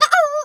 pgs/Assets/Audio/Animal_Impersonations/chicken_2_bwak_02.wav at master
chicken_2_bwak_02.wav